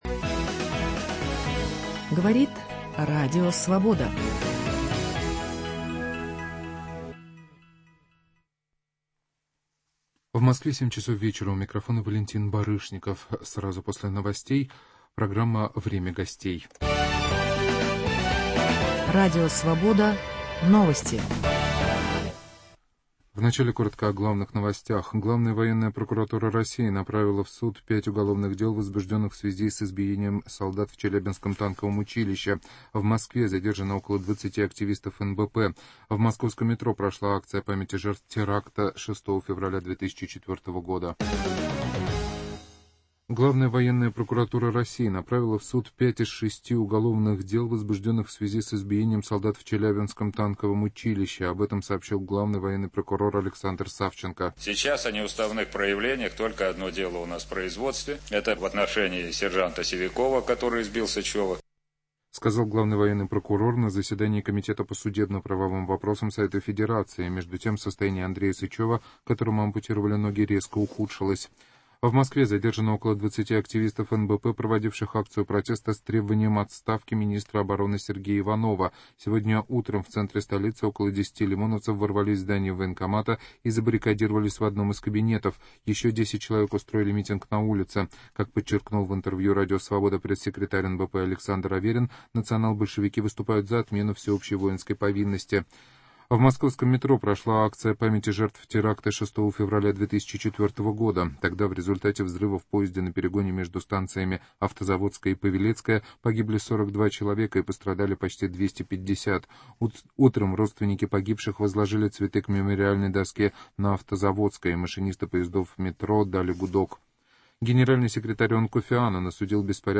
Виталий Портников беседует с кандидатом в президентыБелоруссии от объединенных демократических сил Александром Милинкевичем